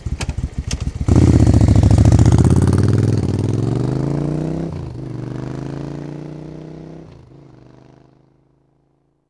Index of /90_sSampleCDs/AKAI S6000 CD-ROM - Volume 6/Transportation/MOTORCYCLE
1200-AWAY.WAV